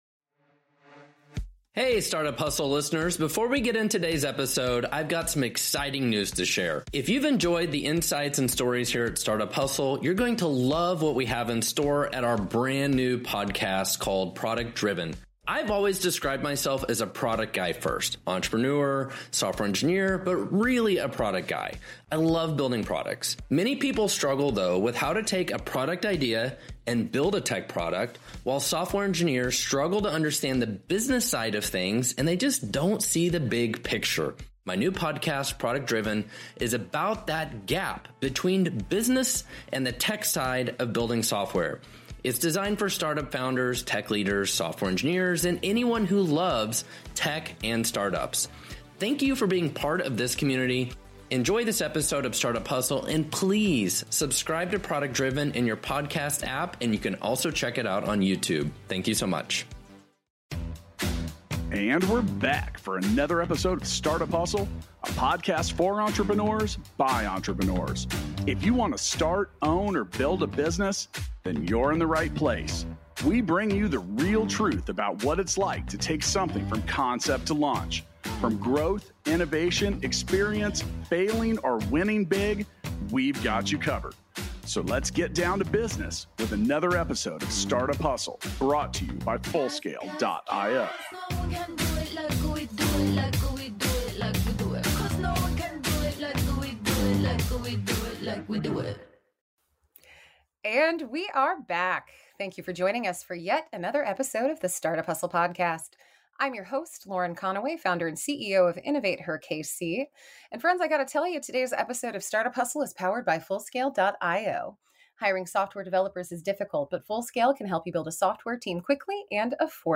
for a conversation about conquering self-doubt and imposter syndrome.